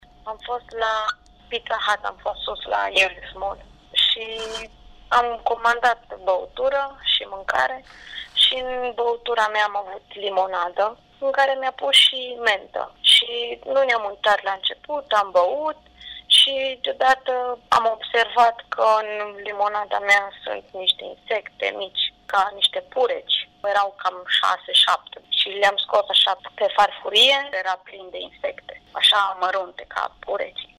Nu ne-am uitat, am băut și deodată am observat că în limonada sunt niște insecte mici ca niște purici, câte șase-șapte și le-am scos pe farfurie”, a declarat tânăra.